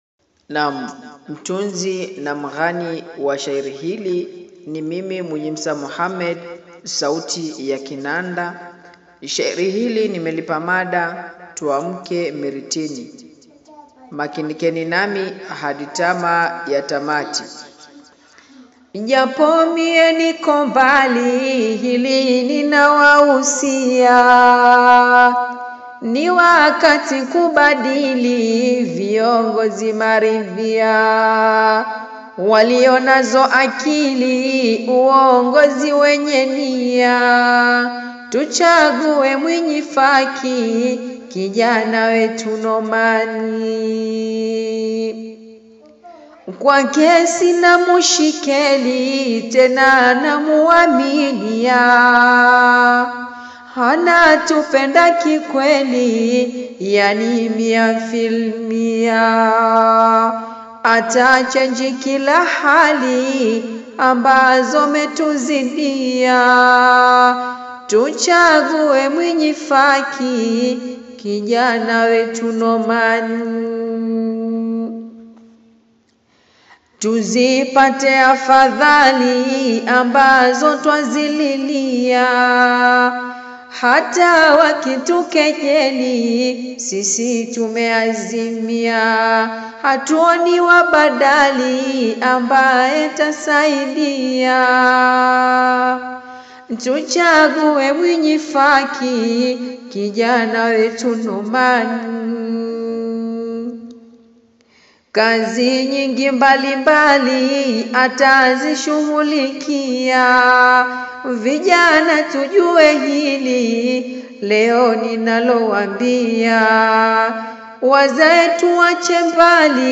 a new sweet melodious Poem